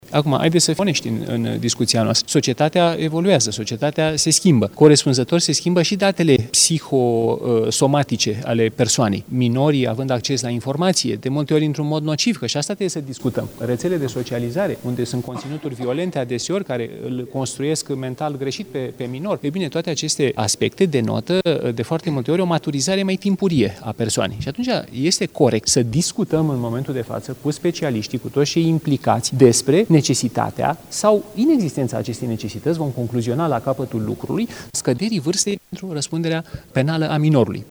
Ministrul Justiției, Radu Marinescu, a declarat că este posibil ca legea să fie schimbată